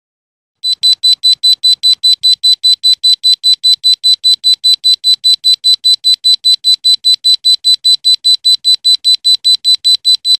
Nyní se ozývá mnohem hlasitěji:
Výše uvedený program lze použít k vytvoření přerušovaného, rovnoměrného tónu.
buzzer_bez_generatora_piezo_7.mp3